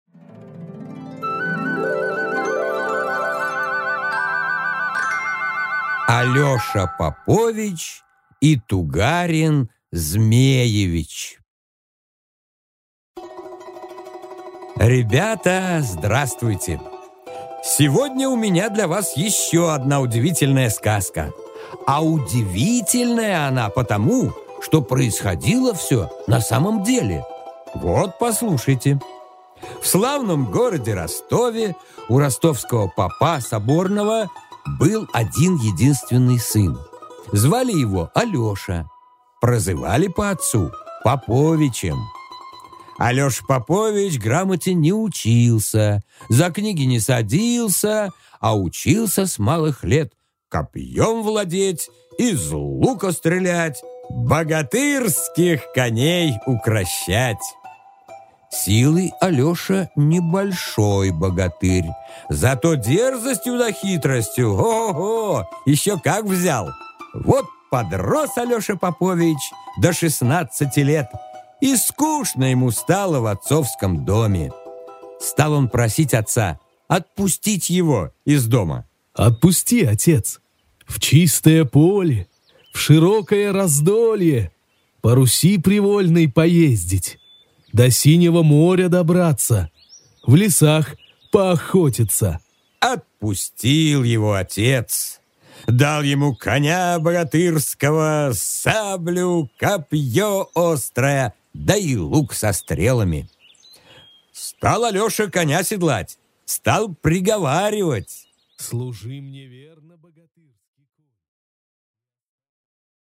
Аудиокнига Алёша Попович и Тугарин Змеевич | Библиотека аудиокниг